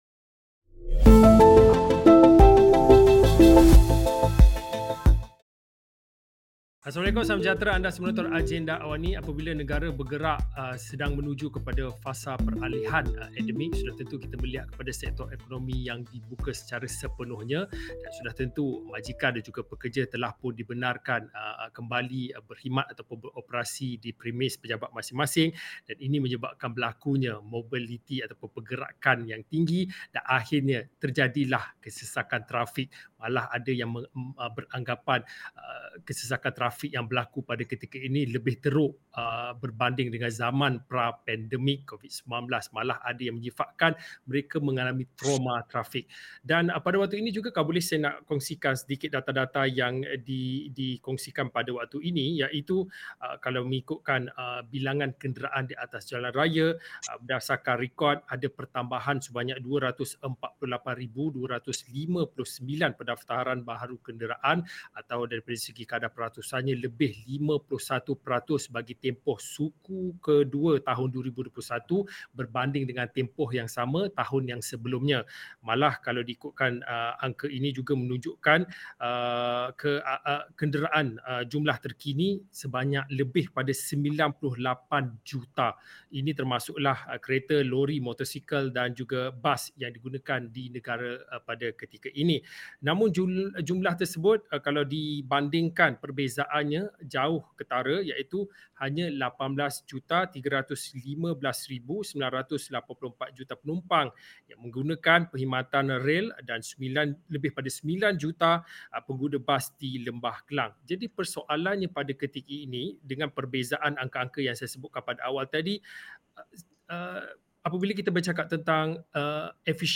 Kesesakan trafik semakin bertambah hingga menyebabkan pengguna alami ‘trauma trafik’, apa penyelesaian segera san strategi jangka masa panjang untuk pengurusan trafik termasuk sistem pengangkutan awam yang lebih efisien di negara ini? Diskusi 9 malam